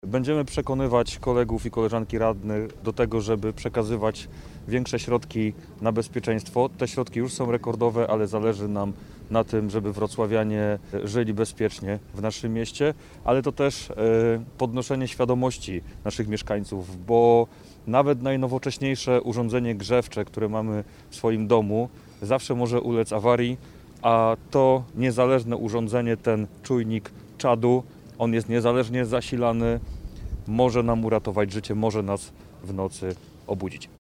Radni zapowiadają, że będą zabiegać o kolejne środki na działania zwiększające bezpieczeństwo mieszkańców. – Te 700 czujników to kolejny przykład systemowego podejścia Wrocławia do bezpieczeństwa – mówi Dominik Kłosowski, radny Lewicy.